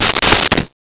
Wood Crack 03
Wood Crack 03.wav